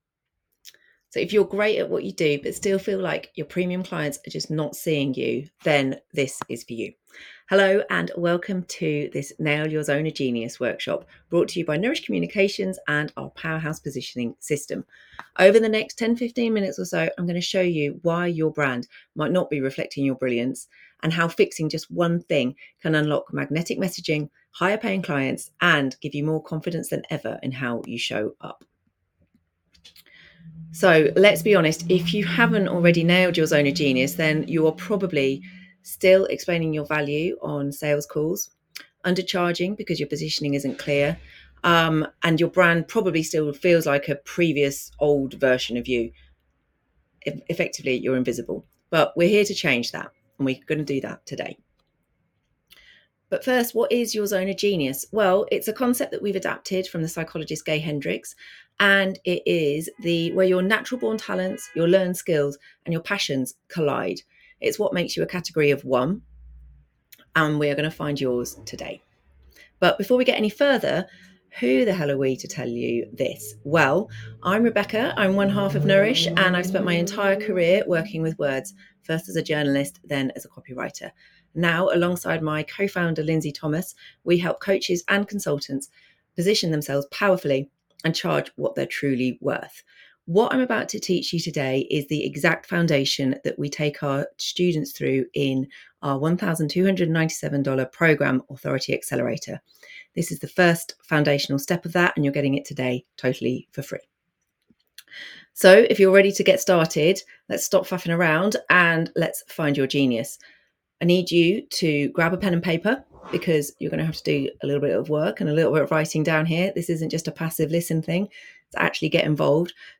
Watch our Nail your Zone of Genius workshop and you'll take the first crucial step towards building a powerful, profitable brand